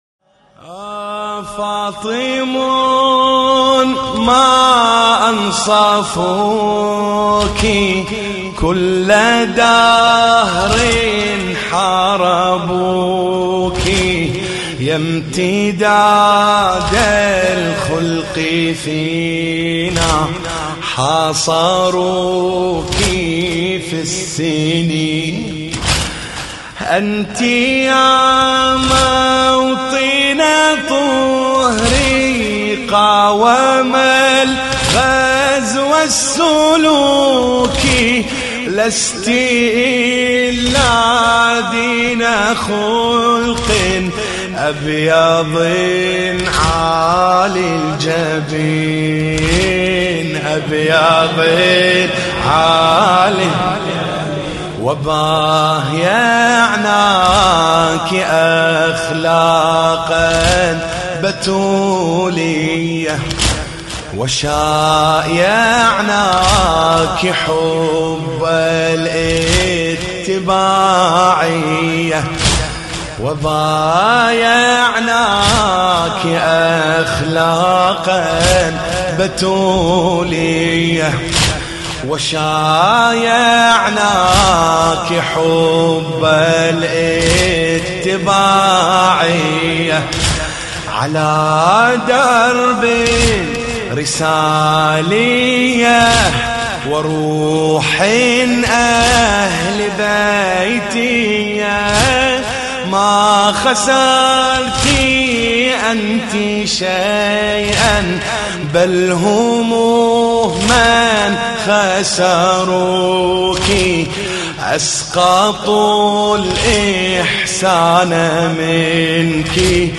الشيخ حسين الاكرف
مراثي